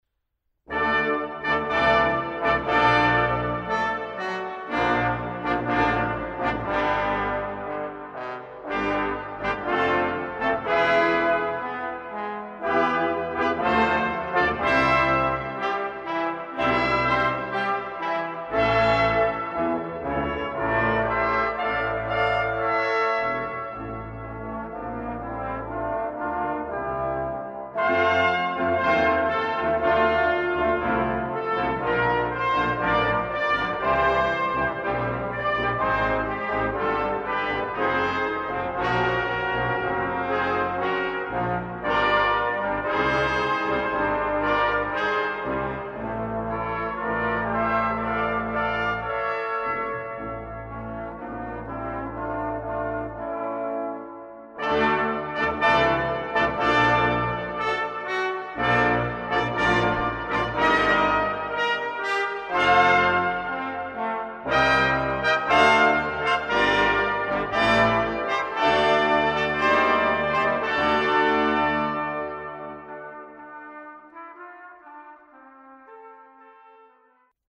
Die Bläserarbeit an St. Michael hat eine lange Tradition.
Unser Klang